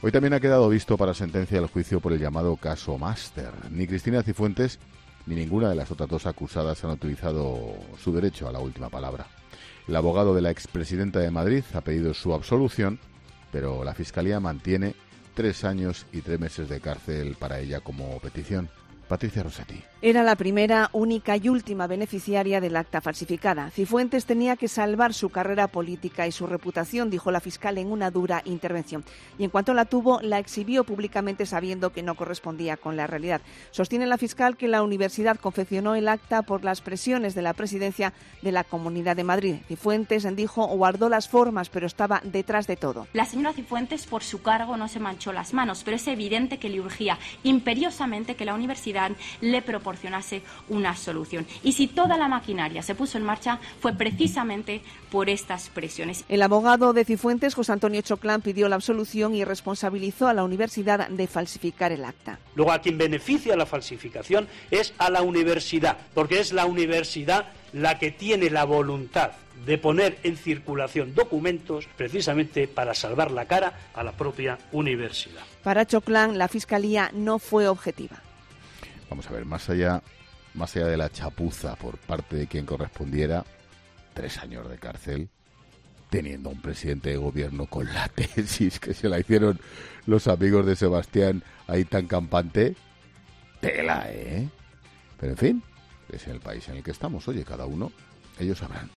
Ángel Expósito ha querido dejar un comentario sobre la polémica en el arranque de 'La Linterna'.